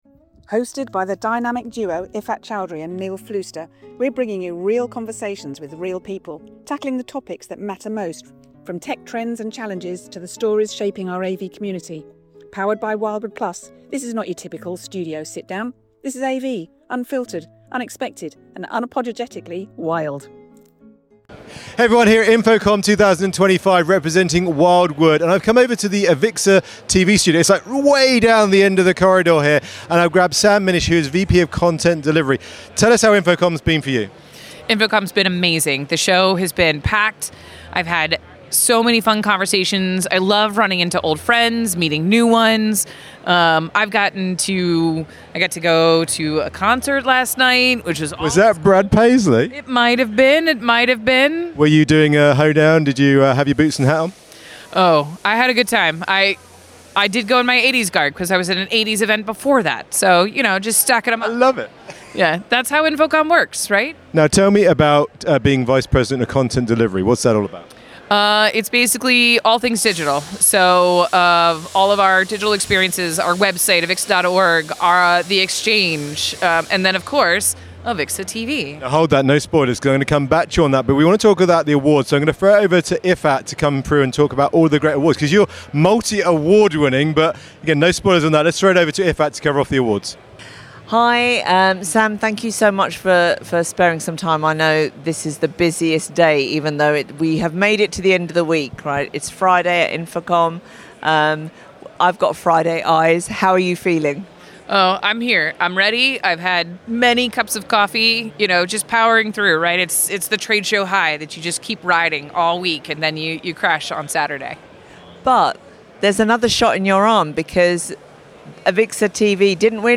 Location: InfoComm 2025, Orlando, USA.